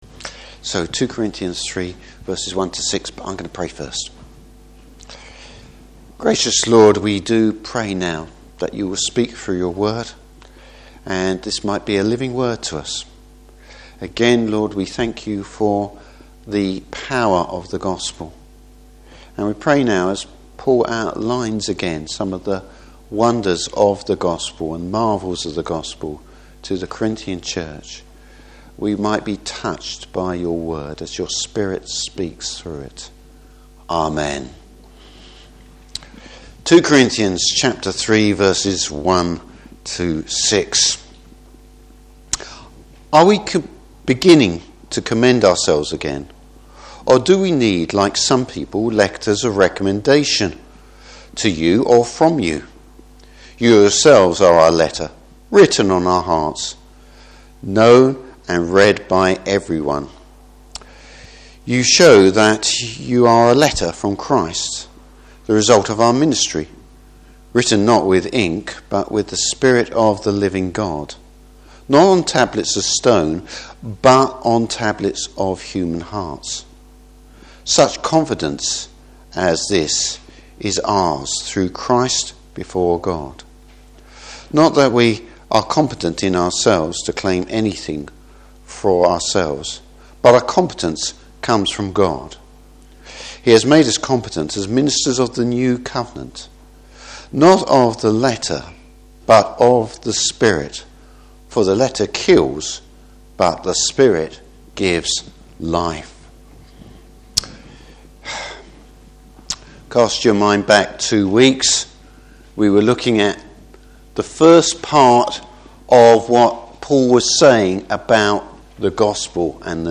Service Type: Morning Service The Holy Spirit’s part in the Word.